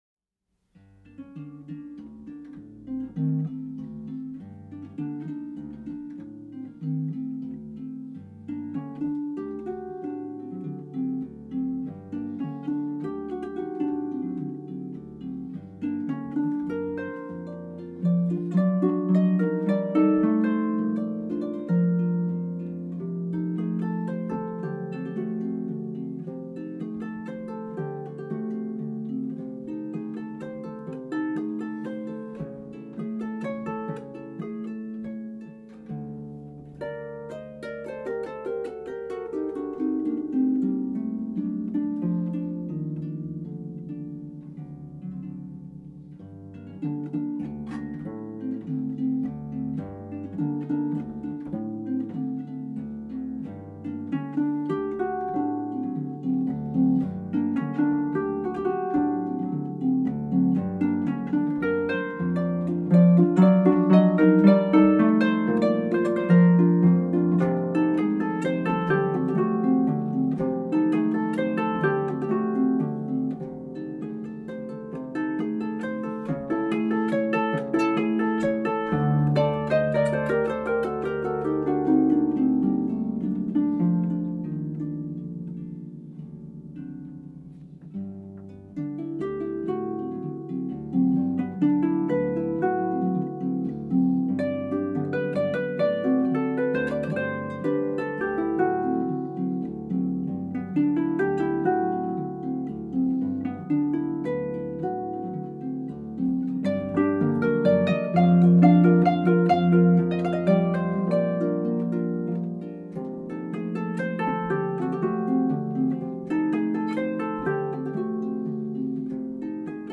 Harpiste